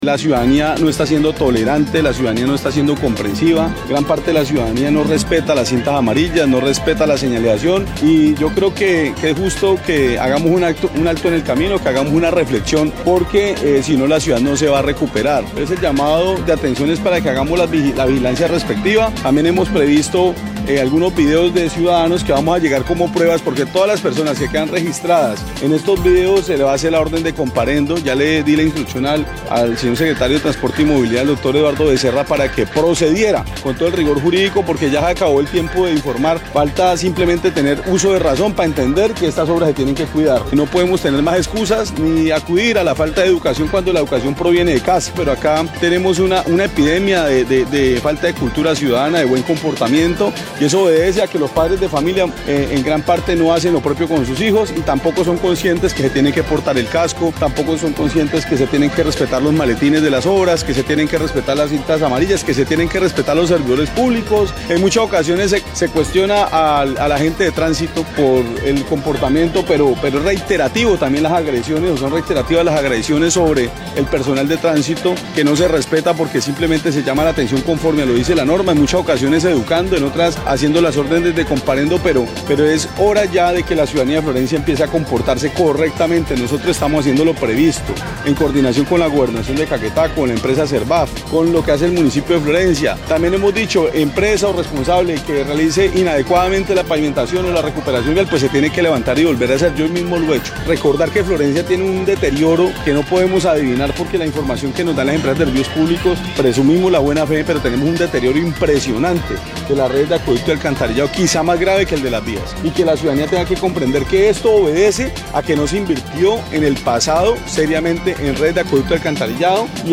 Así lo dio a conocer el alcalde Monsalve Ascanio, quien dijo, además, que, la ciudad padece de una ´epidemia de falta de cultura ciudadana´, misma que se da por la falta de educación y autoridad al interior de las familias.
01_ALCALDE_MONSALVE_ASCANIO_VIAS.mp3